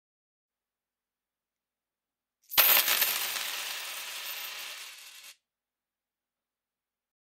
دانلود صدای افتادن یا ریختن سکه 2 از ساعد نیوز با لینک مستقیم و کیفیت بالا
جلوه های صوتی